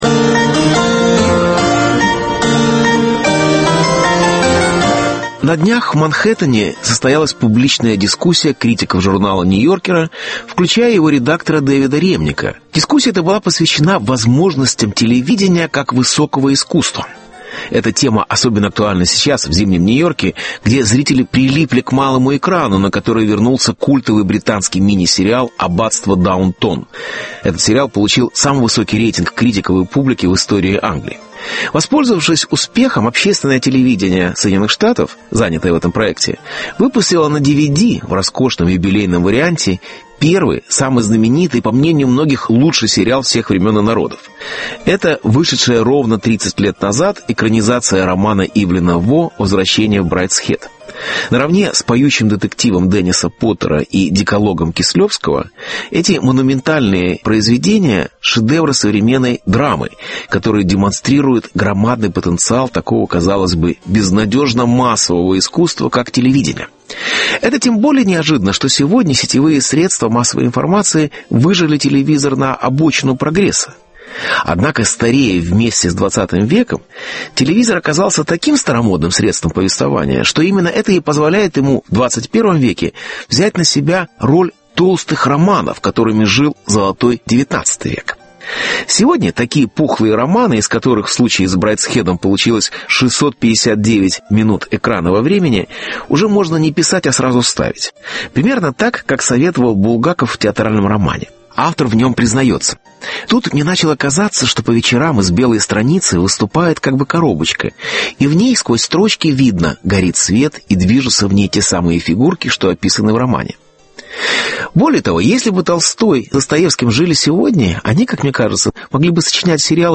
Сериал как высокое искусство (Беседа с Борисом Парамоновым)